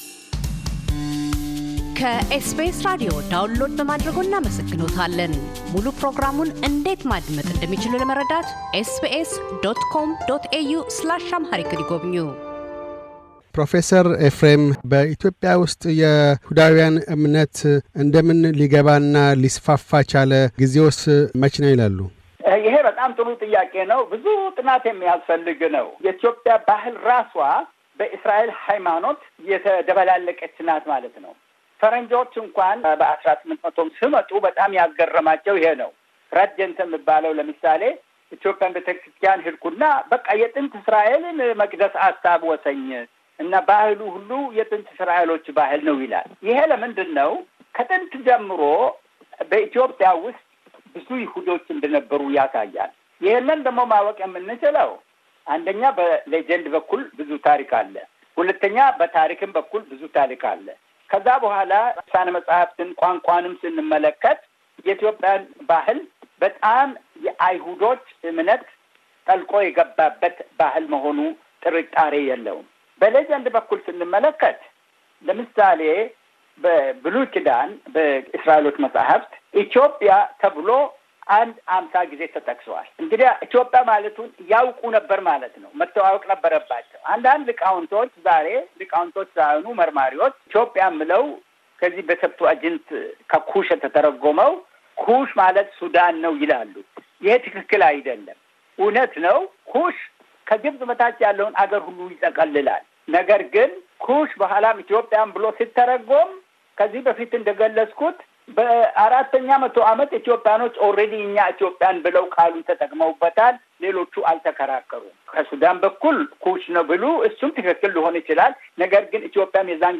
ፕሮፌሰር ኤፍሬም ይስሐቅ፤ ስለ አይሁድ እምነት በኢትዮጵያ ይናገራሉ።